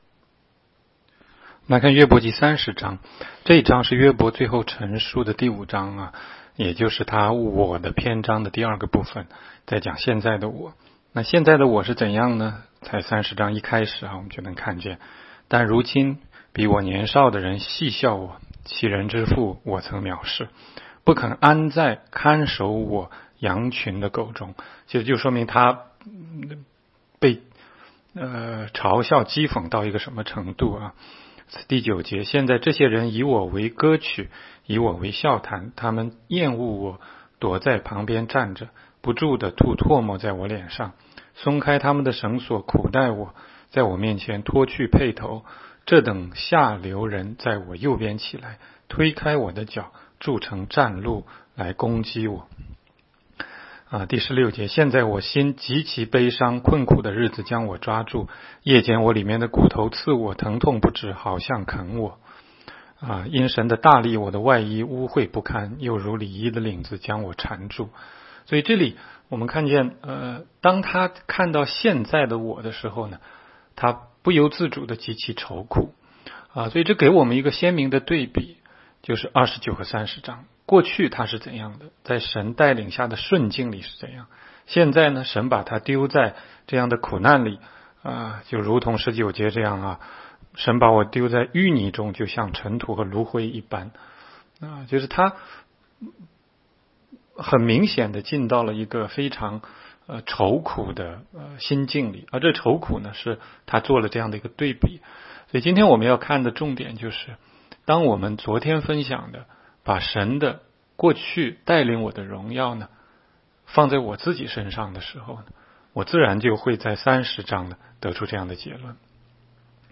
16街讲道录音 - 每日读经-《约伯记》30章